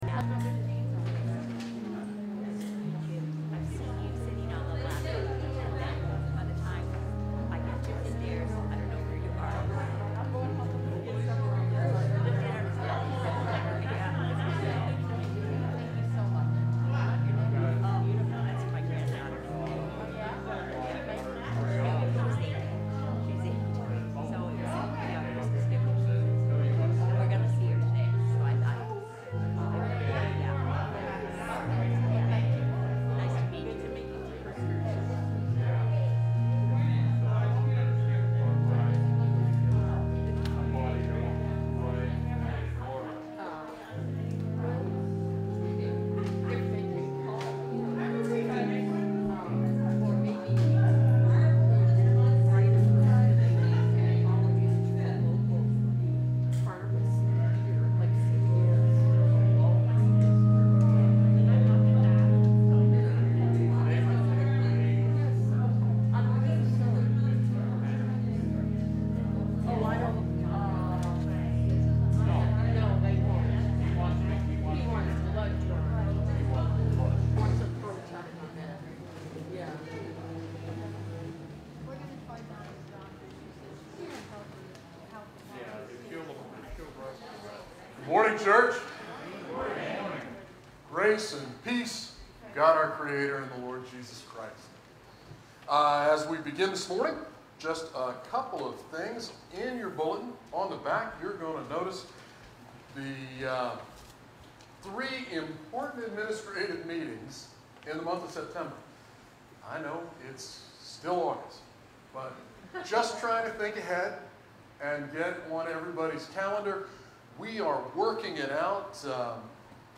We welcome you to either virtual or in-person worship on Sunday, August 22, 2021 at 10am!
Sermons